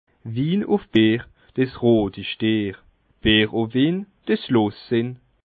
Bas Rhin
Ville Prononciation 67
Schiltigheim